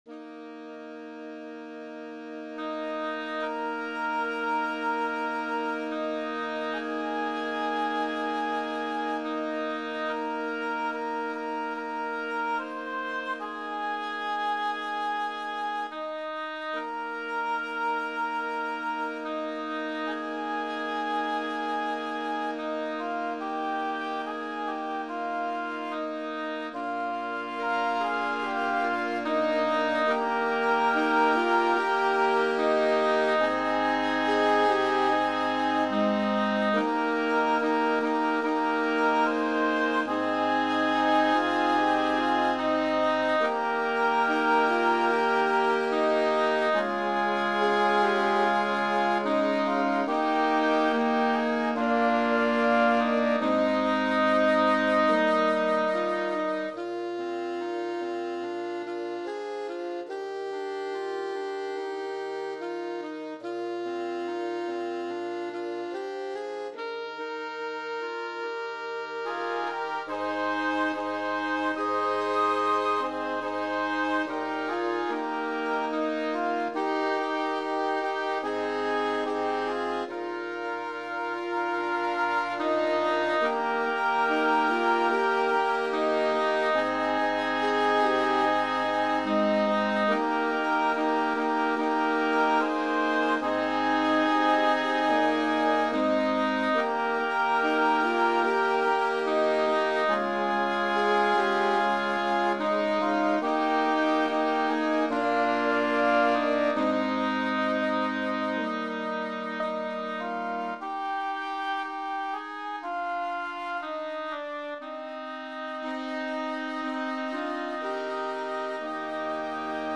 Voicing: Woodwind Quintet